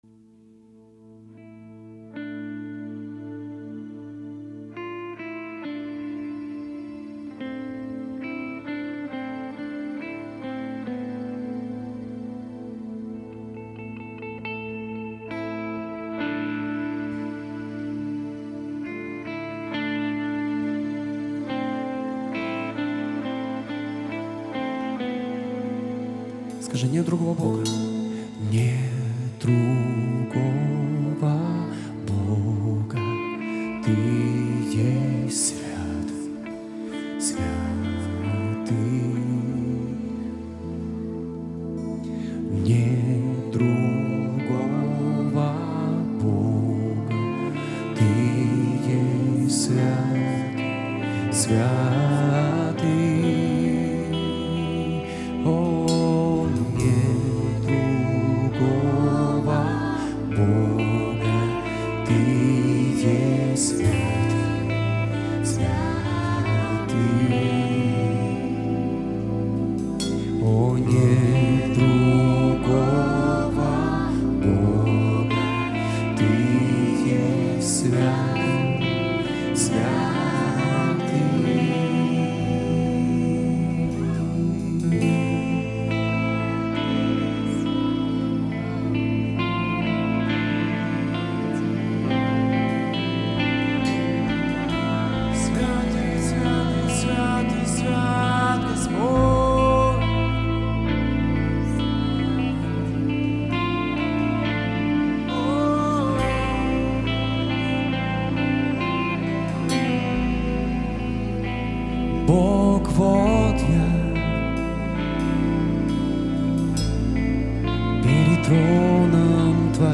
BPM: 100